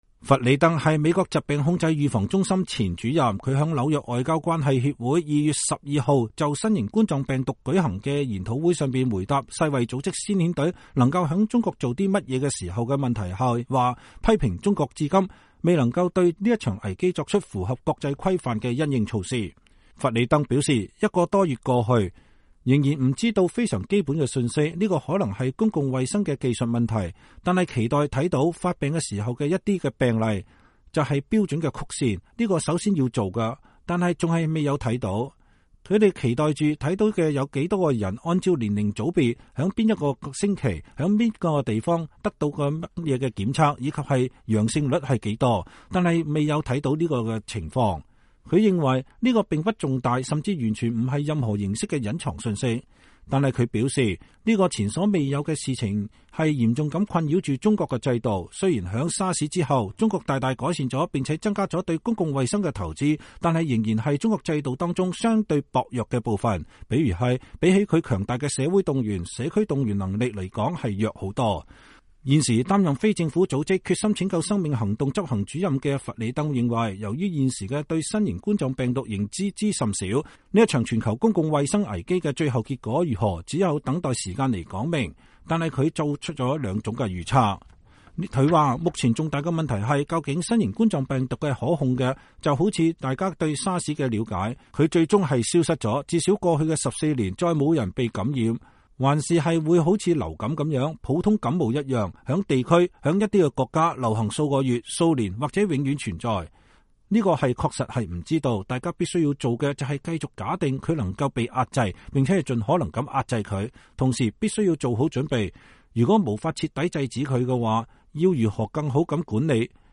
托馬斯·弗里登（Thomas R. Frieden）是美國疾病控制預防中心前主任，他在紐約外交關係協會2月12日就新冠病毒舉行的研討會上回答“世衛組織先遣隊能在中國做些什麼”的問題時，批評中國至今未對這場危機作出符合國際規範的因應措施。